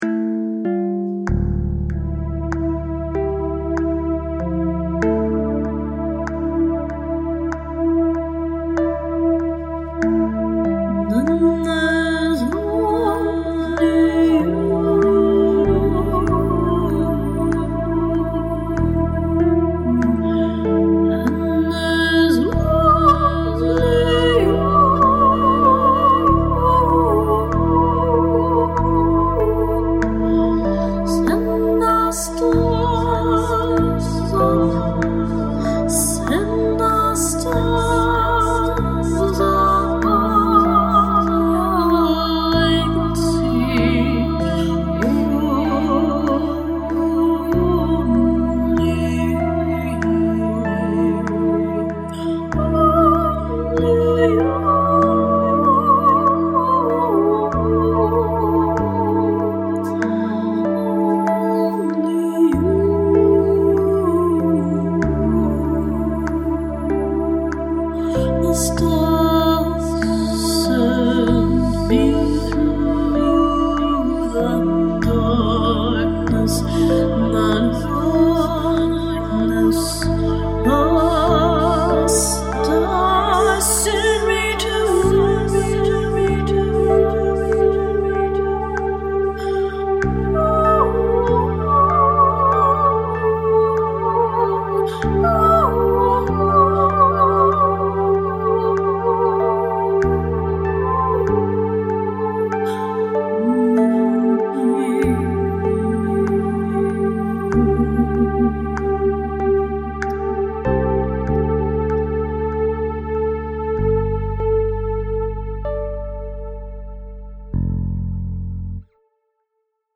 The Stars Send Me to You (voice/electronic)